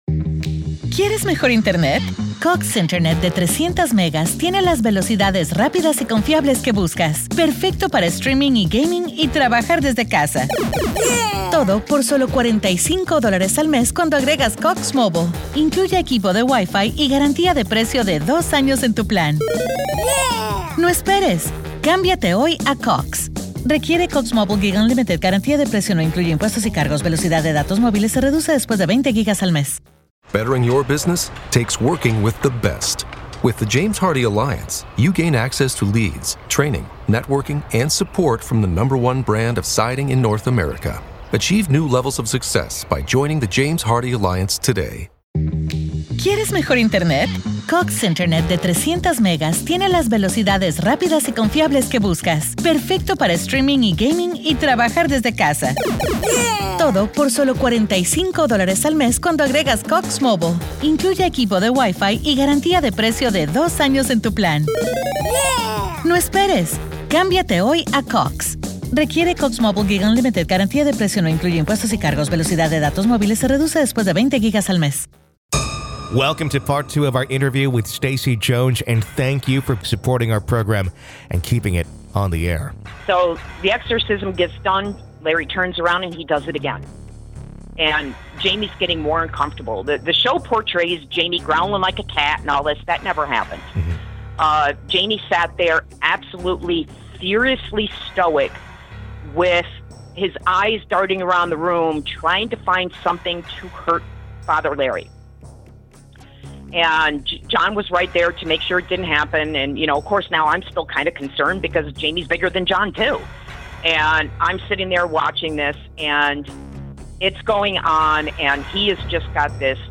This conversation dives into the unsettling space where faith, fear, and the possibility of demonic influence intersect.